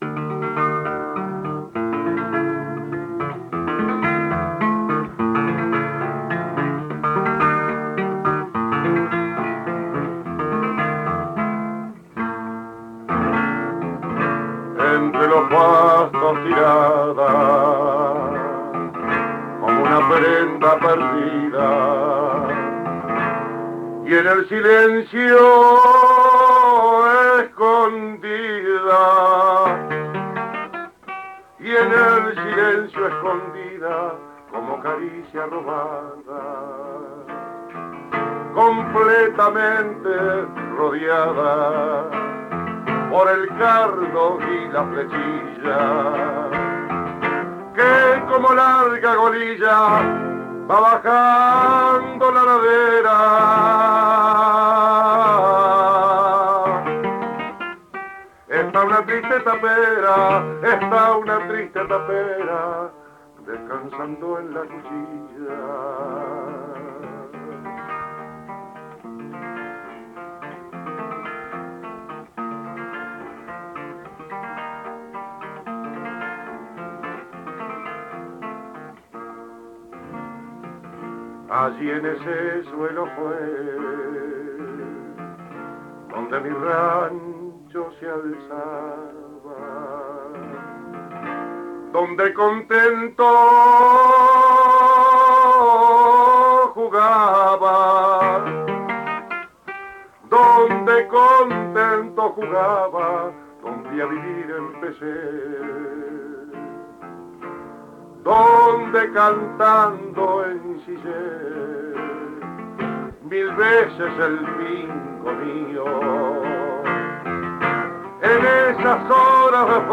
canto y guitarra
Formato original de la grabación: cinta magnética a 9,5 cm/s